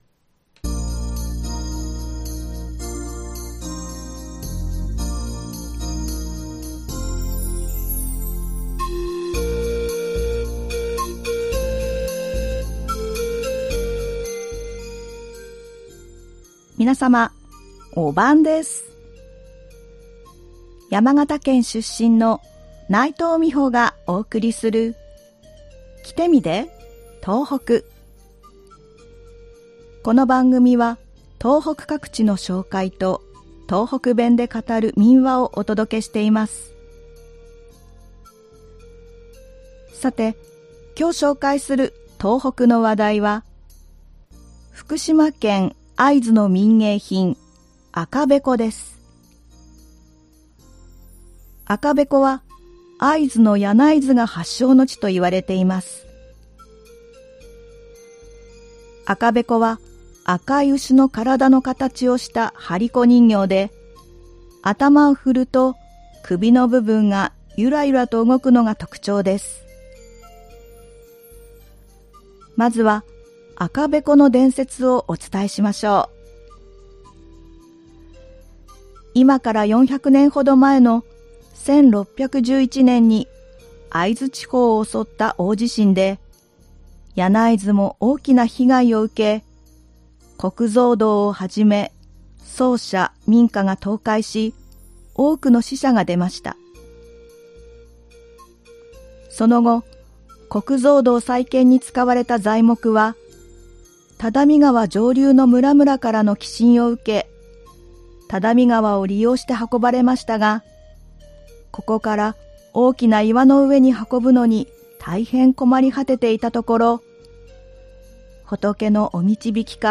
この番組は東北各地の紹介と、東北弁で語る民話をお届けしています（再生ボタン▶を押すと番組が始まります）